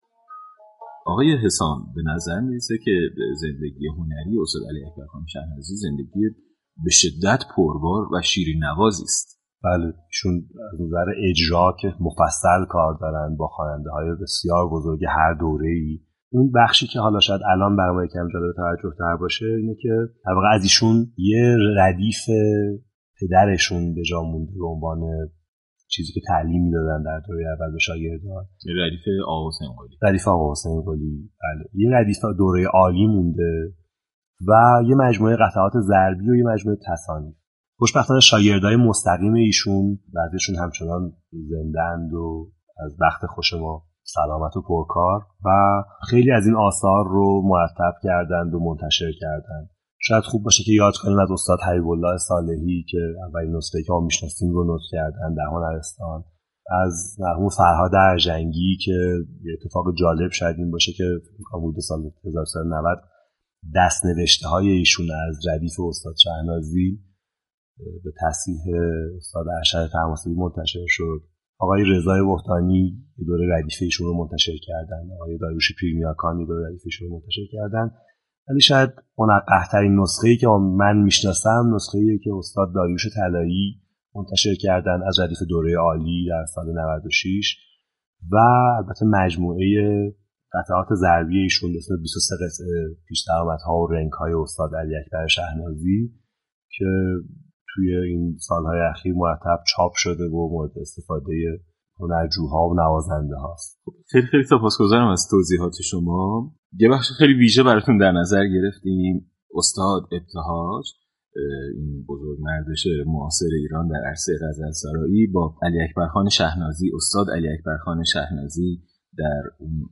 داریوش طلایی از شاگردان استاد شهنازی و مهمان تلفنی برنامه با اشاره به تاریخ ساز نار در ایران خاطر نشان كرد : ساز تار مهمترین ساز ایرانی در 200 سال گذشته بوده و خاندان فراهانی از جمله آقا حسین قلی، پدر استاد شهنازی، نقش اساسی در تدوین و گسترش ساز تارایفا كرده اند .